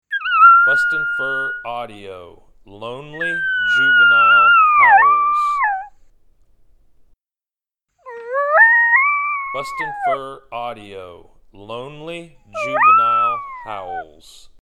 BFA Lonely Juvenile Howls
Young male coyote lone howling.
BFA Lonely Juvenile Howls Sample.mp3